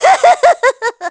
Voice Clip of Hammer Bro laughing from Mario Party ds
MPDS_HammerBro-laugh.wav